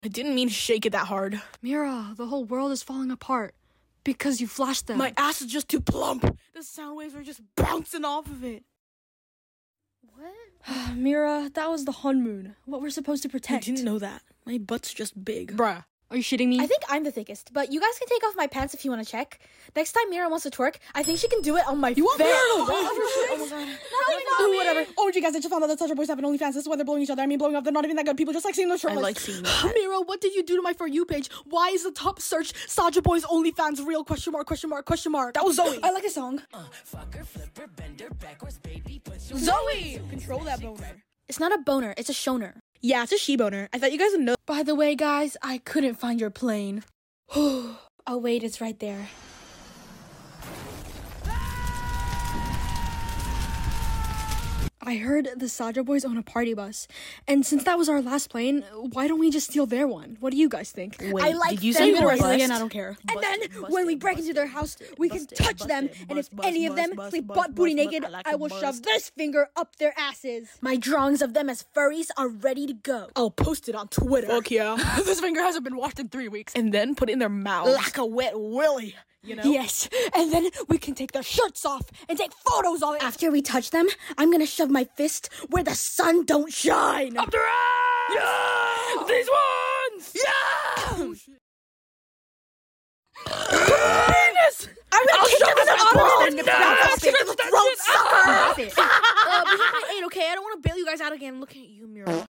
Kpop Demon Hunters Voiceover